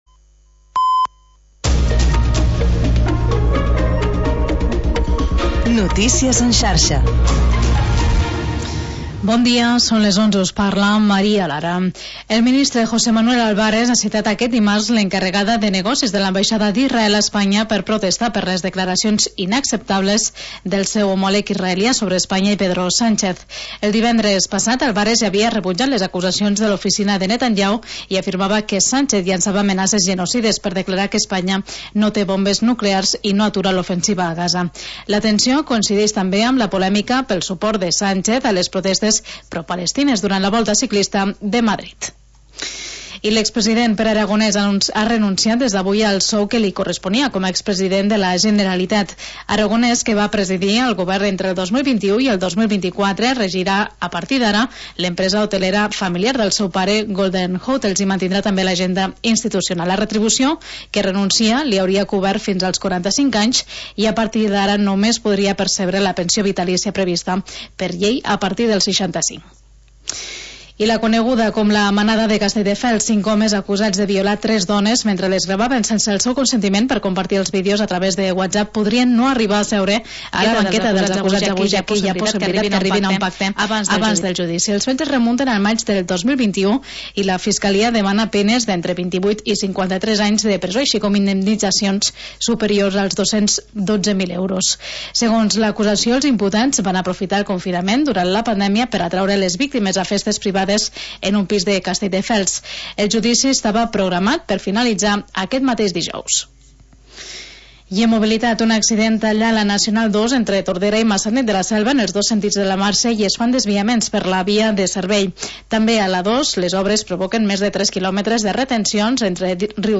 Magazín d'entreteniment per encarar el dia